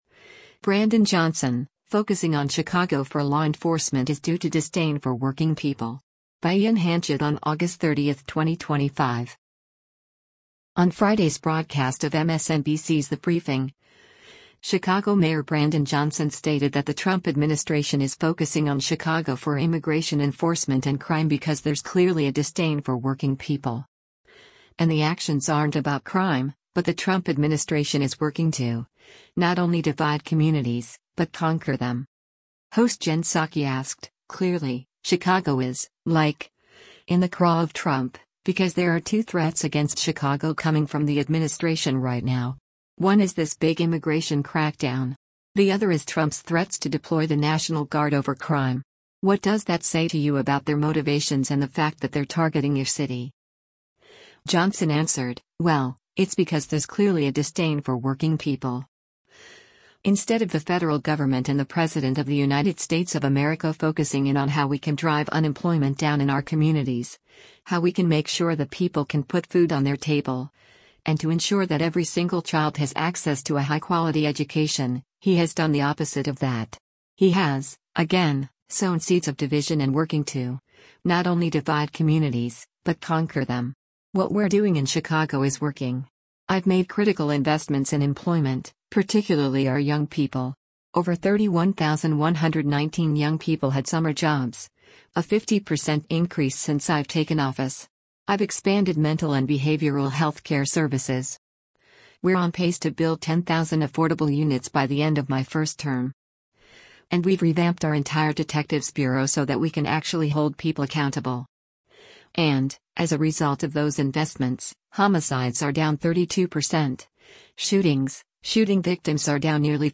On Friday’s broadcast of MSNBC’s “The Briefing,” Chicago Mayor Brandon Johnson stated that the Trump administration is focusing on Chicago for immigration enforcement and crime “because there’s clearly a disdain for working people.”